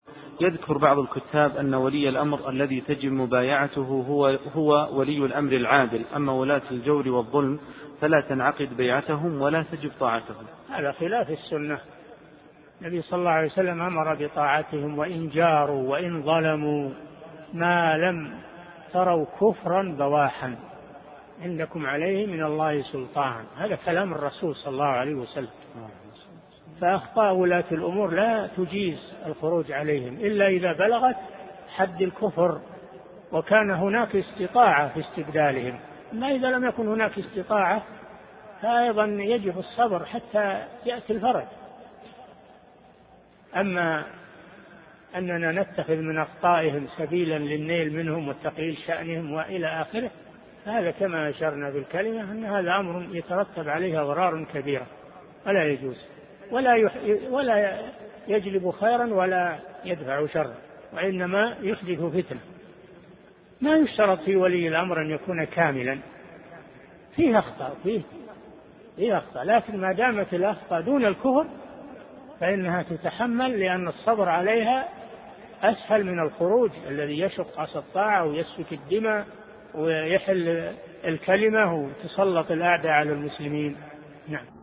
Download audio file Downloaded: 312 Played: 3843 Artist: الشيخ صالح الفوزان Title: الرد على من قال أن ولي الأمر الظالم لا يطاع و إنما يطاع الحاكم العادل Length: 1:25 minutes (194.7 KB) Format: MP3 Mono 16kHz 16Kbps (CBR)